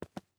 ES_Footsteps Concrete 10.wav